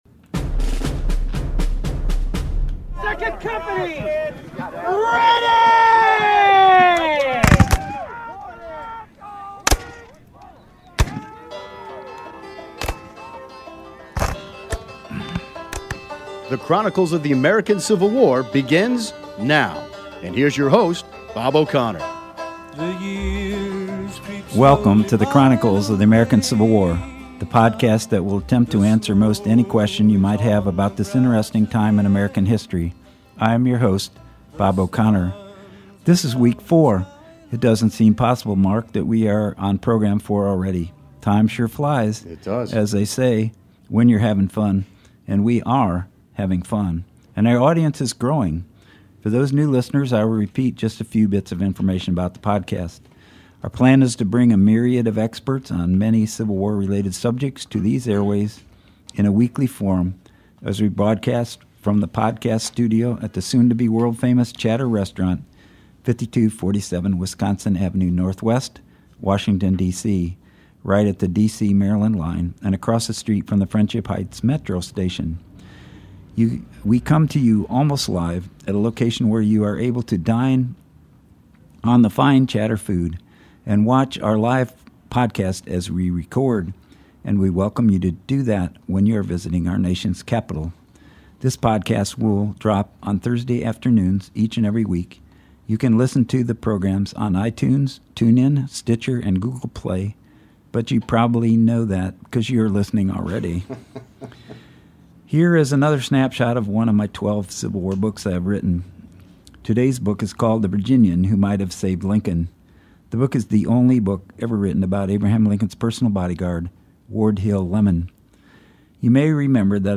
Historian